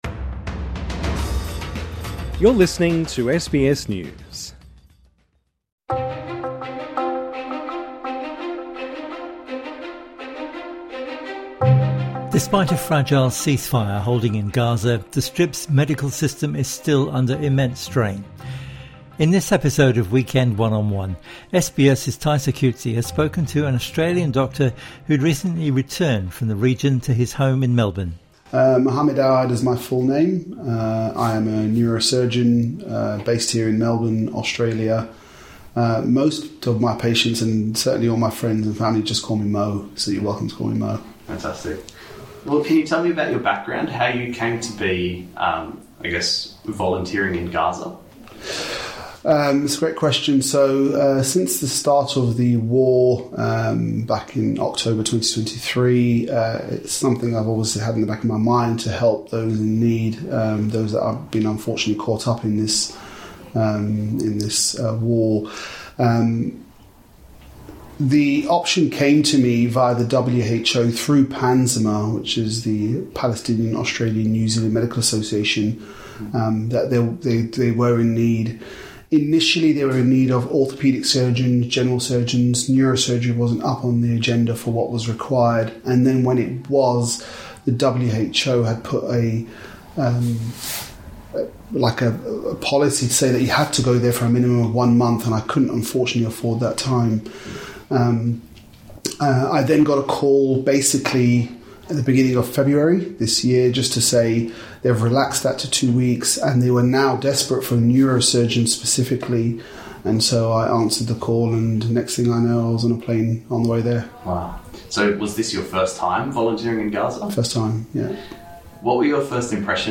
INTERVIEW: Working as a doctor in Gaza